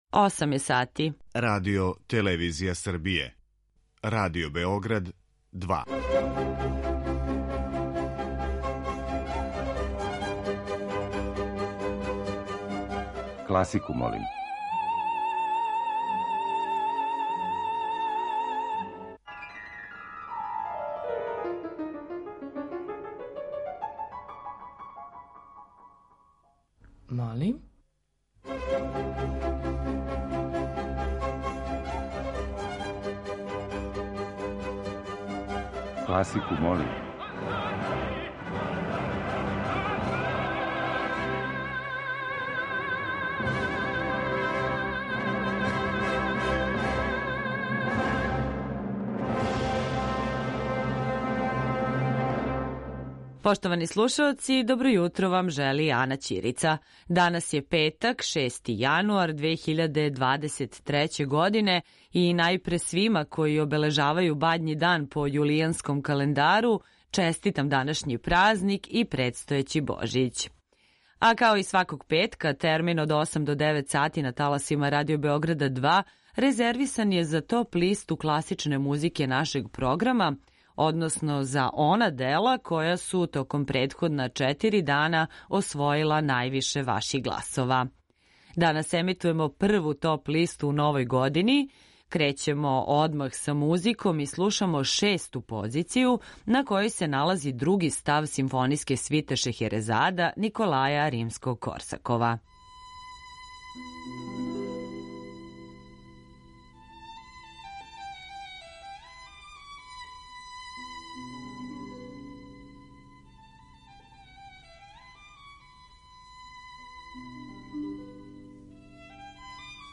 После сабирања гласова које смо примили од понедељка до четвртка, емитујемо топ листу класичне музике Радио Београда 2, односно композиције које су се највише допале слушаоцима.
klasika.mp3